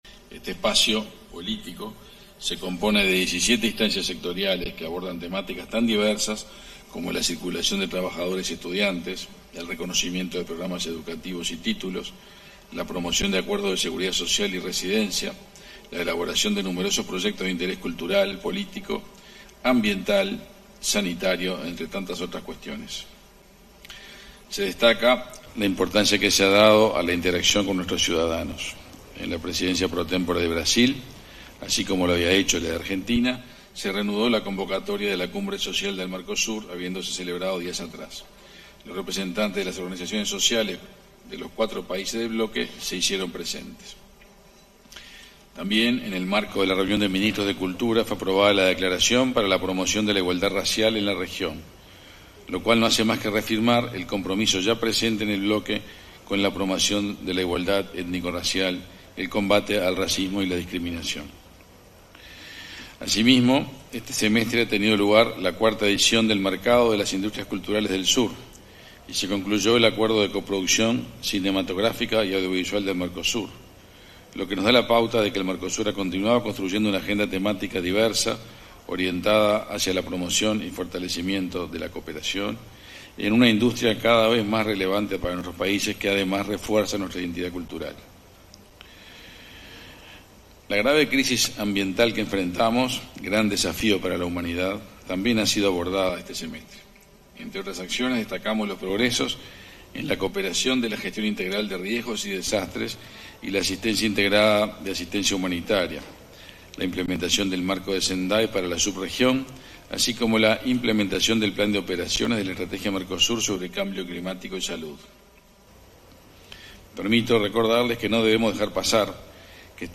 Palabras del canciller uruguayo Omar Paganini
El canciller uruguayo Omar Paganini, disertó, este miércoles 6 en Brasil, durante la LXIII Reunión de Ministros de Relaciones Exteriores de Estados